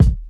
• Classic Hot Hip-Hop Kick Sample D# Key 124.wav
Royality free kick drum sound tuned to the D# note. Loudest frequency: 182Hz
classic-hot-hip-hop-kick-sample-d-sharp-key-124-Jj5.wav